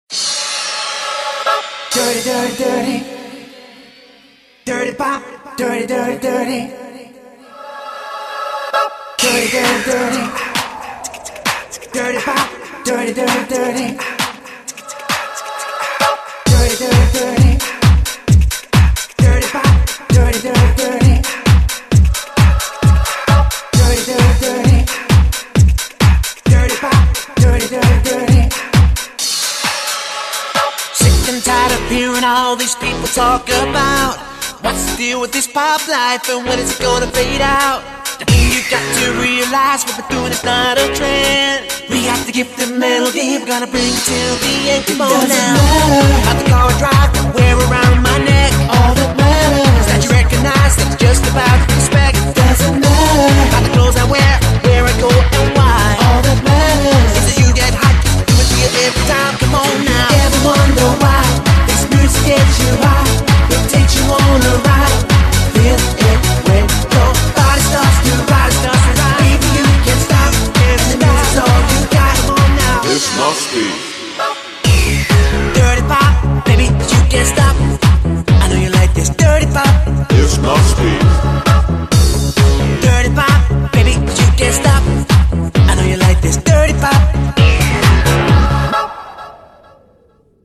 TypePadPop
BPM132
Audio QualityLine Out